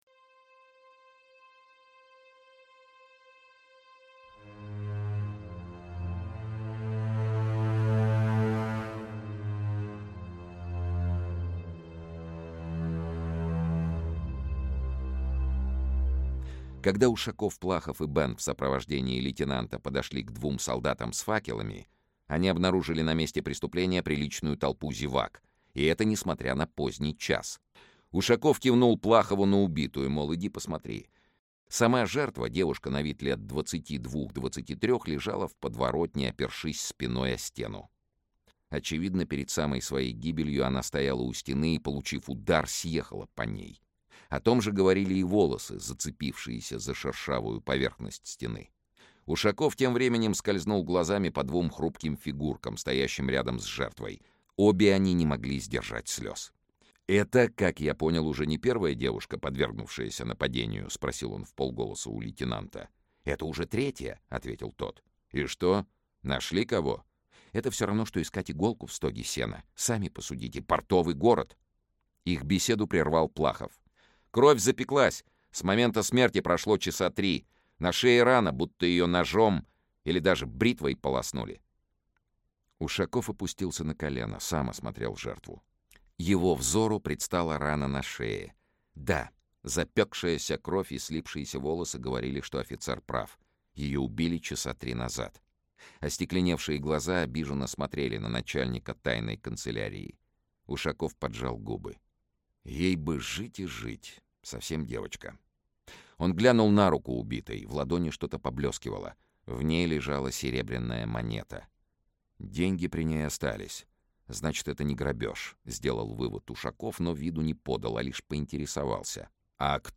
Аудиокнига Нерассказанные истории. Бостонский потрошитель | Библиотека аудиокниг
Бостонский потрошитель Автор Олег Рясков Читает аудиокнигу Сергей Чонишвили.